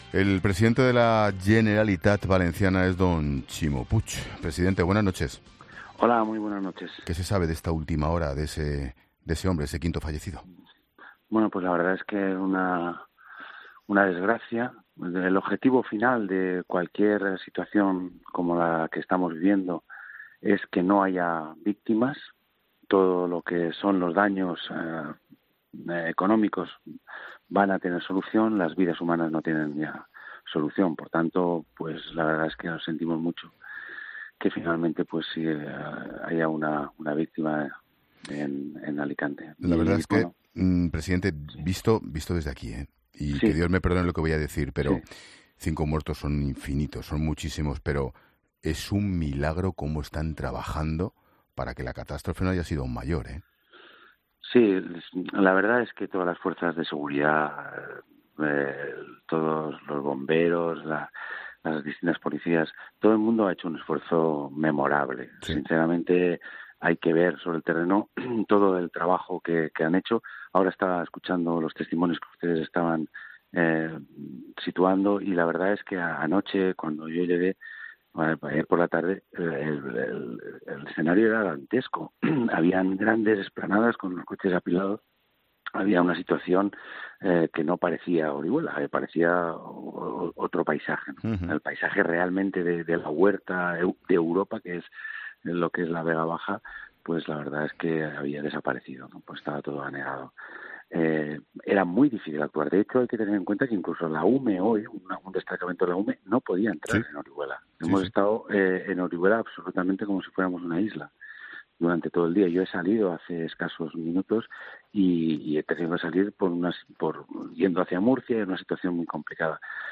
El presidente de la Comunidad Valenciana, Ximo Puig, ha estado en 'La Linterna' de COPE para hacer balance de la situación: “Es una desgracia. El objetivo principal es que no haya víctimas. Todo los daños económicos tienen solución pero la perdida de vidas humanas no. Todas las fuerzas de seguridad han hecho un esfuerzo memorable. La verdad que es que anoche el escenario era dantesco y un escenario en el que era muy difícil actuar. Sinceramente estos momentos son de unidad y de eso debemos estar orgullosos”.